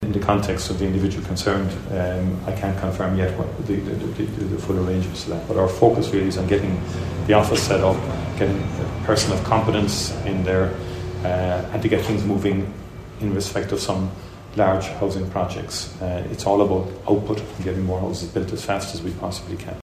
The Taoiseach says he’s just focusing on getting people into houses: